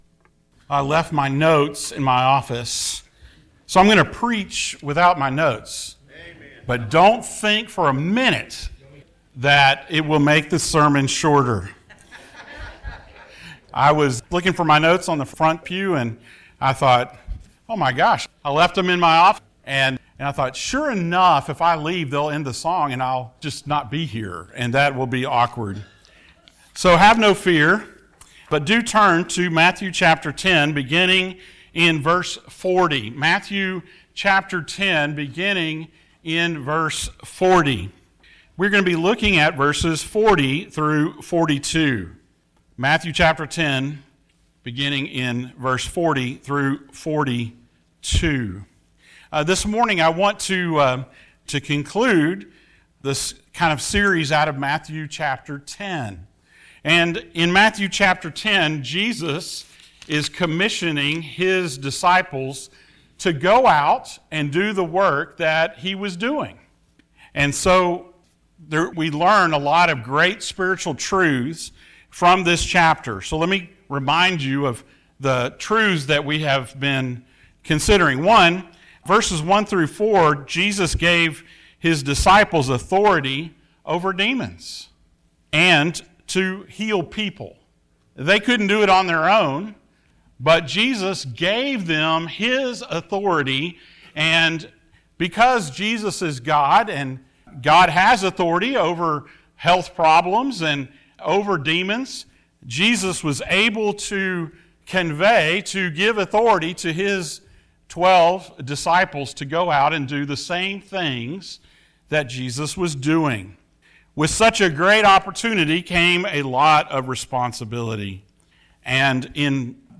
The Life and Ministry of Jesus Passage: Matthew 10:40-42 Service Type: Sunday Morning Thank you for joining us.